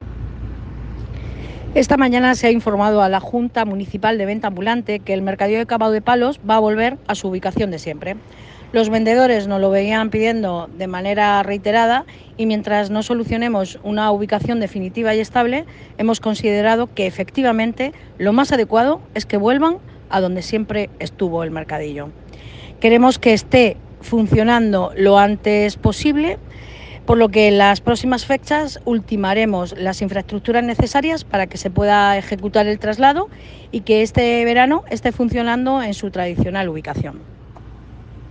Enlace a Declaraciones de Belén Romero.